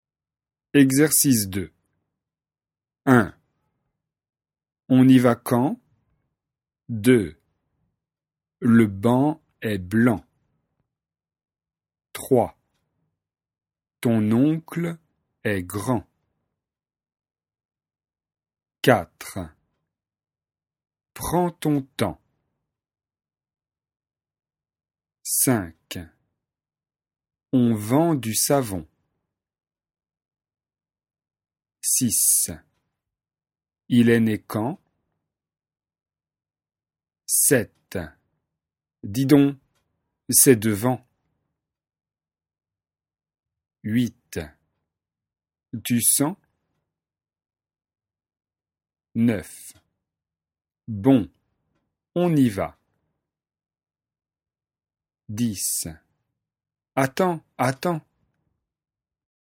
Exercice de prononciation.
Différence entre [en, an] et [on].